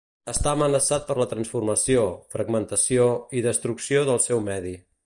Pronounced as (IPA)
[fɾəɡ.mən.tə.siˈo]